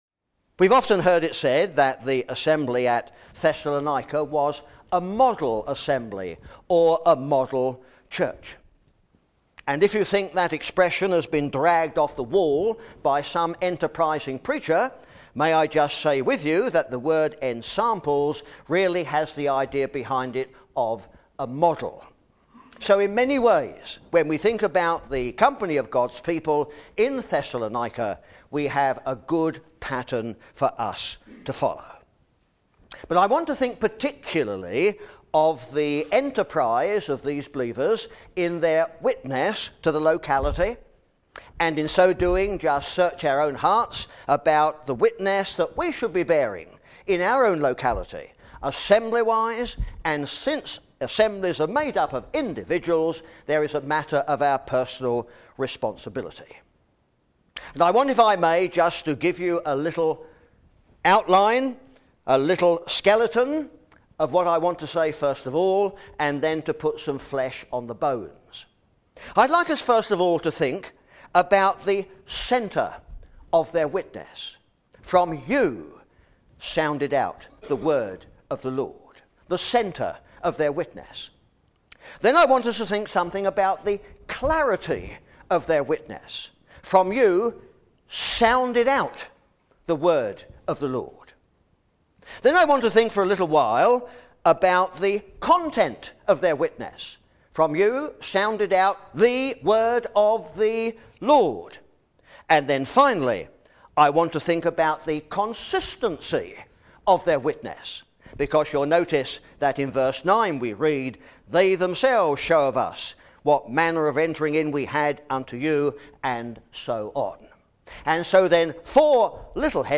The centre, clarity, content and consistency of their witness from 1 Thess 1:7-10. (Recorded in Lendrick Muir, Scotland, 2006)